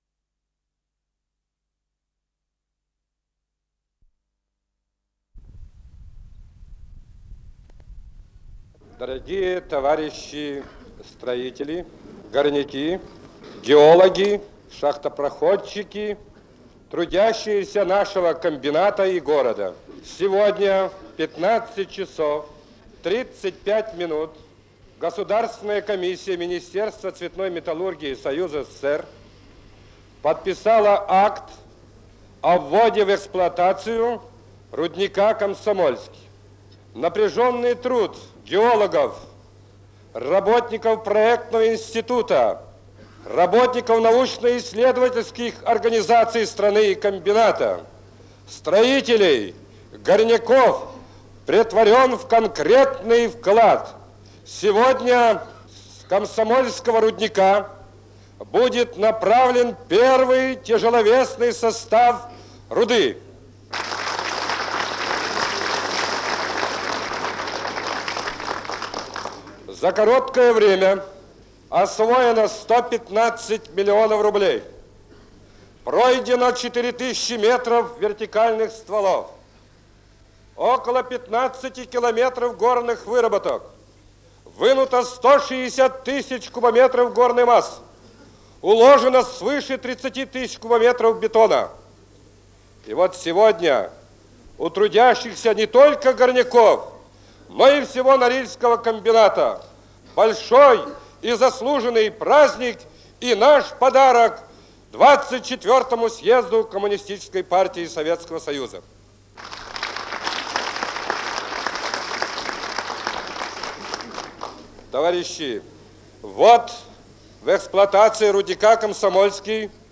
с профессиональной магнитной ленты
Скорость ленты19 см/с
ВариантМоно